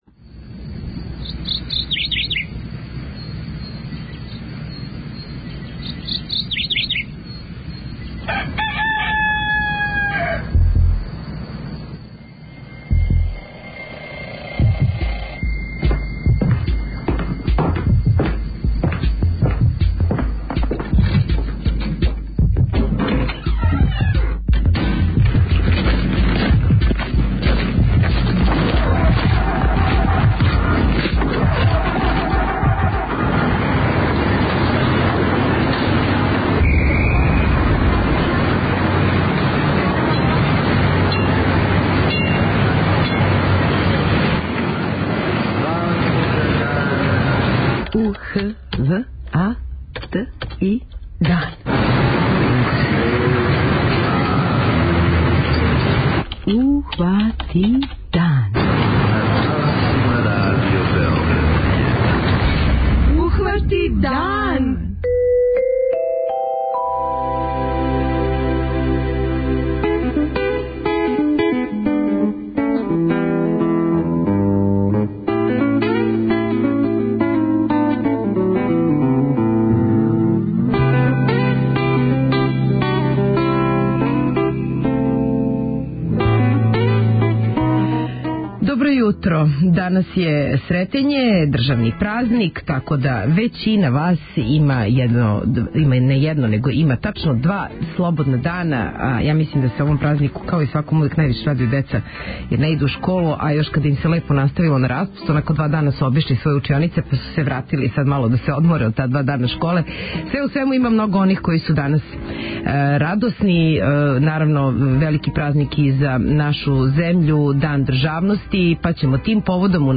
У јутарњем програму чућете разговоре са представницима ових институција.
преузми : 21.58 MB Ухвати дан Autor: Група аутора Јутарњи програм Радио Београда 1!